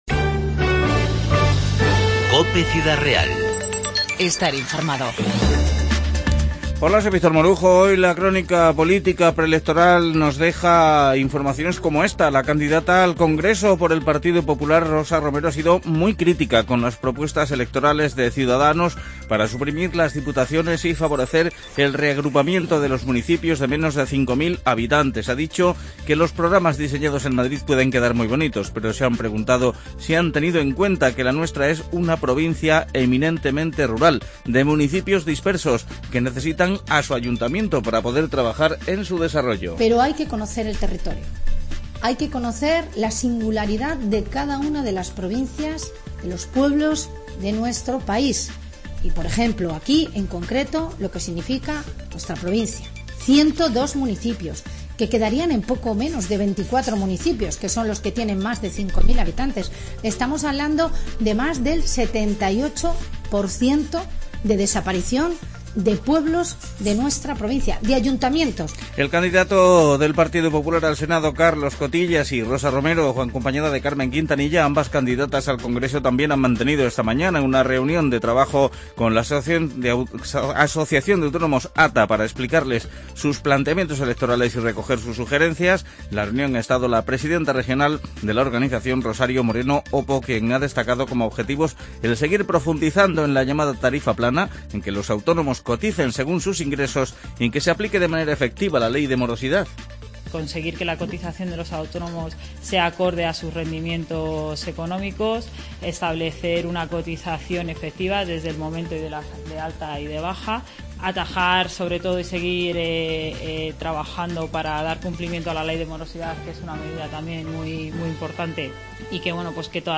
INFORMATIVO 10-11-15